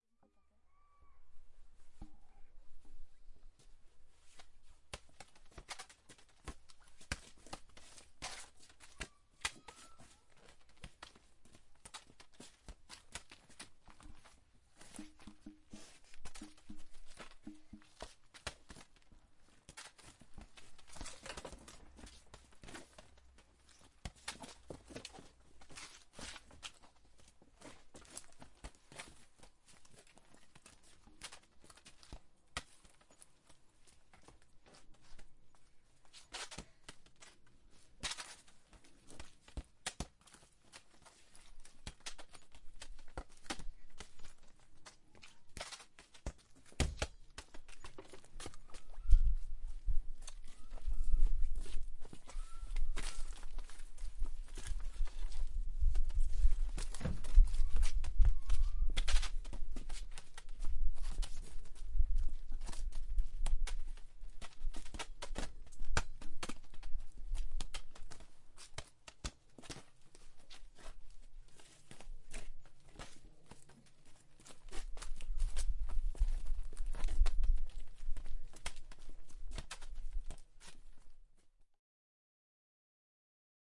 花园 " 9.气氛在水泥地上踢球
描述：气氛，橡胶球，混凝土花，两个人，外面，遥远